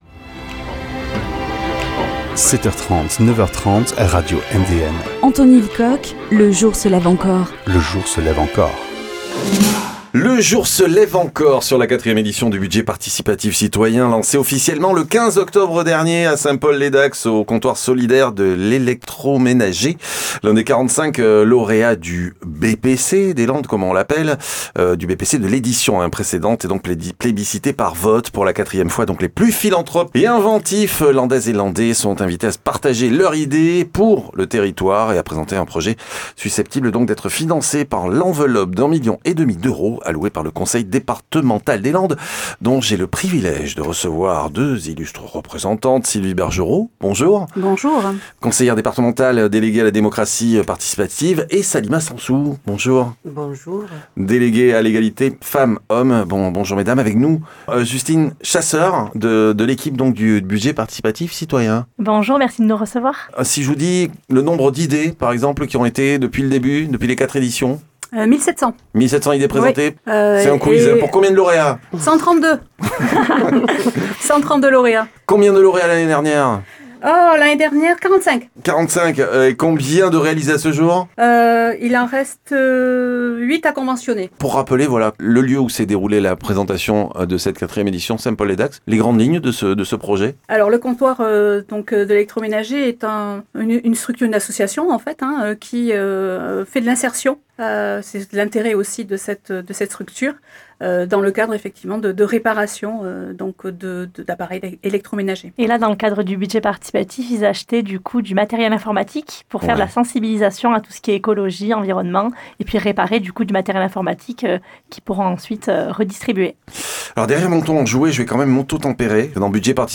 Les nouveautés et conseils présentées ici par les conseillères départementales Sylvie Bergeroo et Salima Sensou